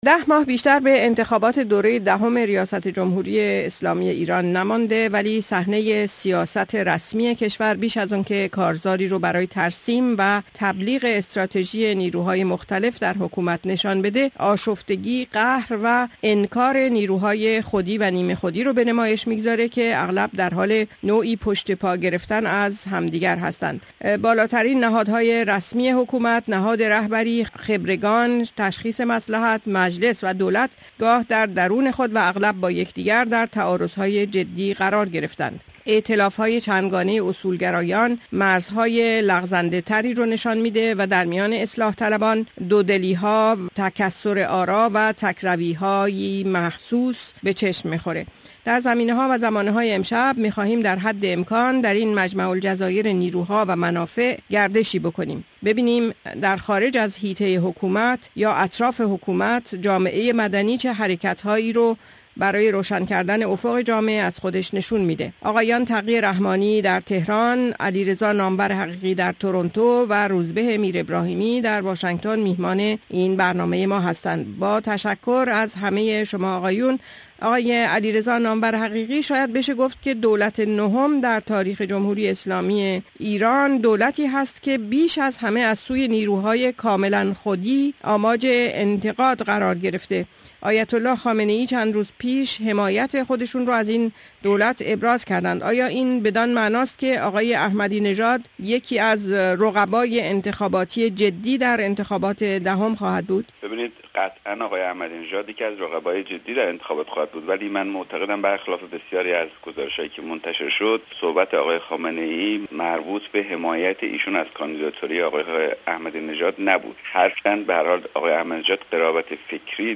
میزگرد رادیوئی